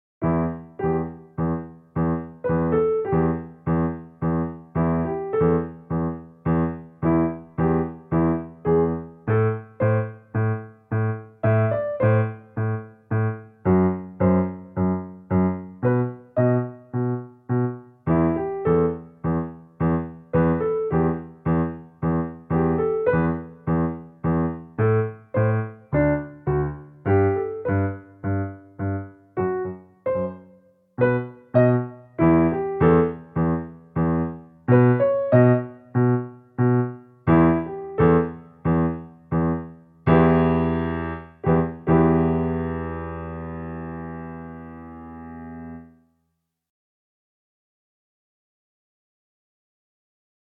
Drei Soli mit Miniskalen
Hier nun sind Aufnahmen mit dem Klavier.